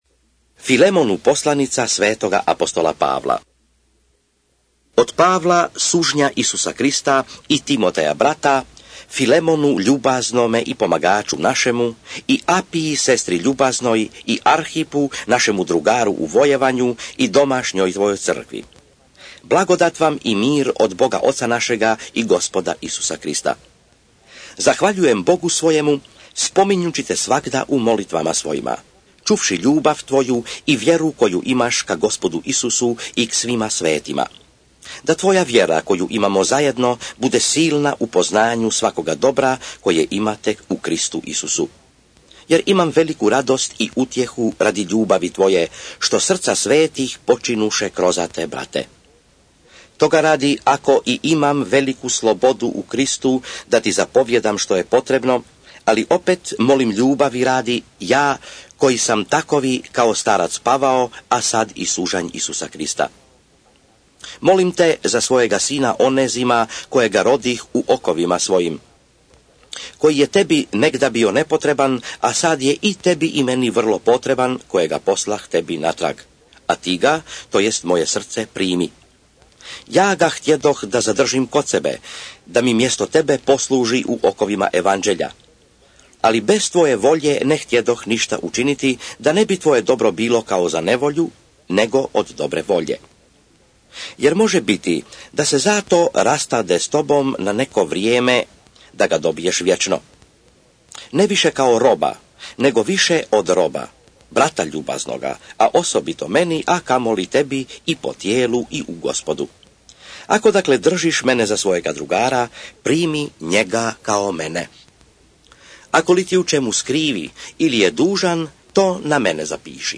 Filimon / SVETO PISMO - čitanje - mp3